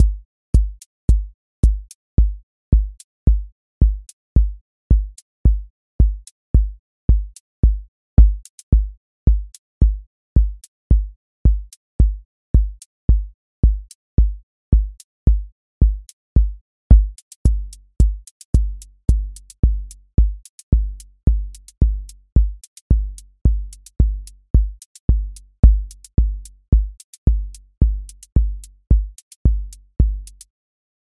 QA Listening Test house Template: four_on_floor
• voice_kick_808
• voice_hat_rimshot
• voice_sub_pulse
Steady house groove with lift return